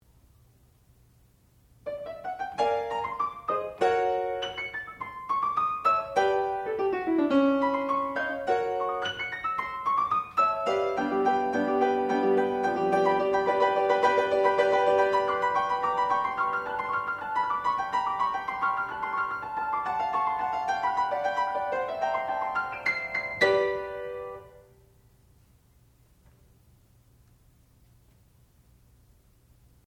sound recording-musical
classical music
piano